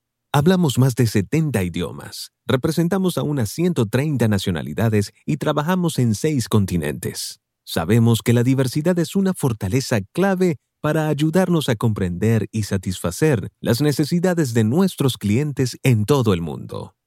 Male
From commercials to narration, audiobooks, podcasts, and radio/TV voiceovers, I offer a neutral Spanish accent that fits a wide range of styles. My voice is smooth, professional, and easy to connect with.
Explainer Videos